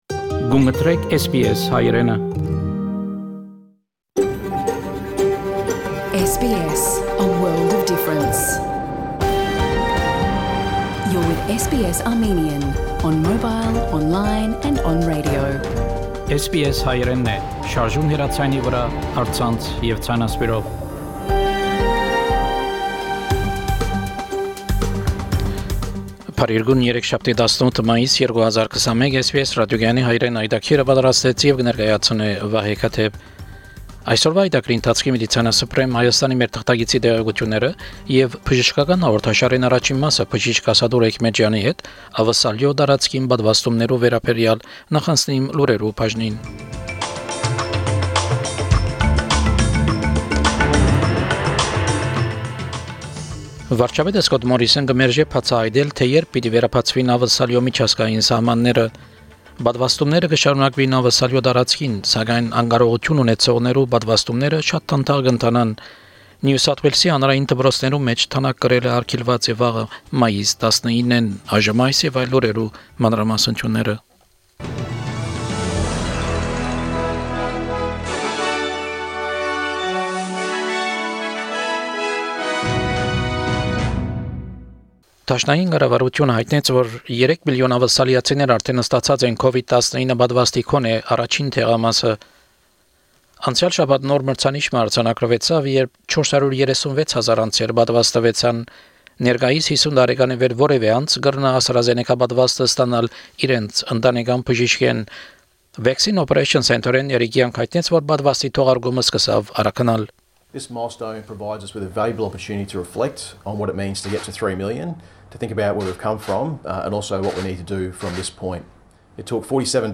SBS Armenian news bulletin – 18 May 2021
SBS Armenian news bulletin from 18 May 2021 program.